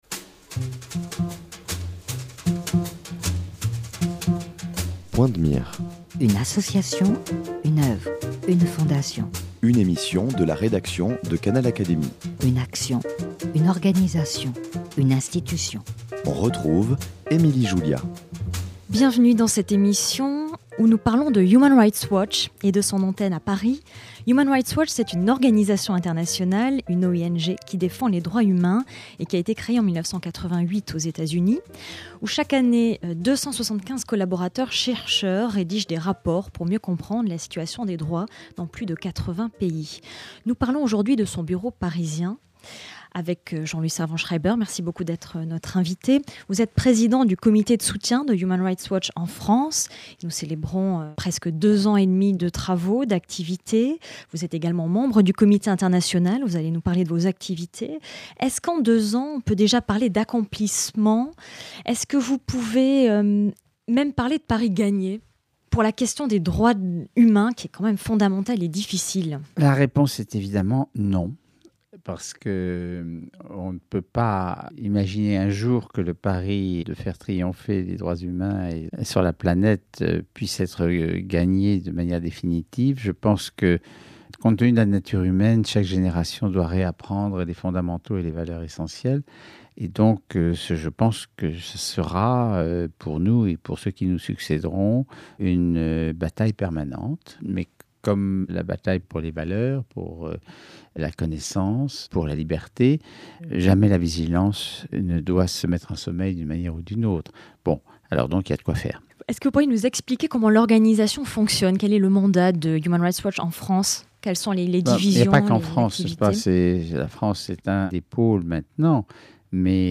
Interview du président du comité de soutien et membre du conseil international : Jean-Louis Servan-Schreiber.